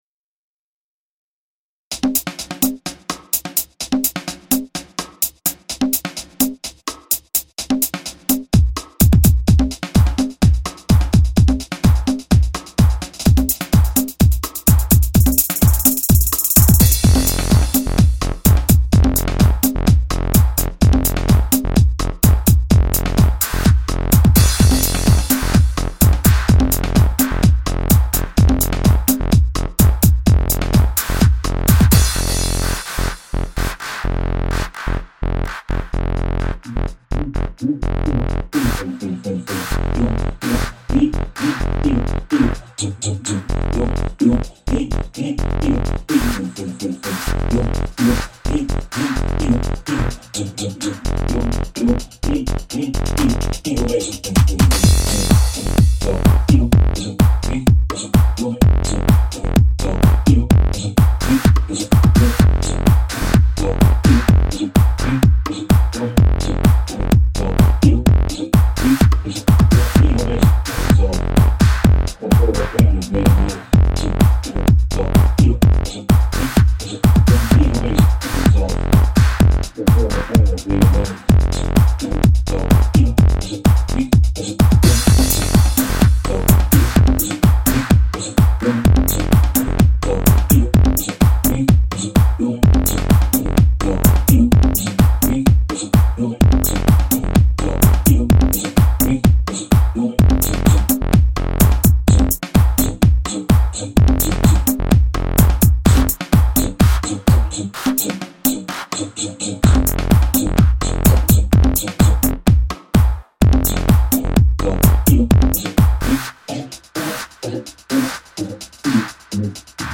Me revoila les amis avec une track mi-house mi tribal .
Les synthés sont vraiment pas mal!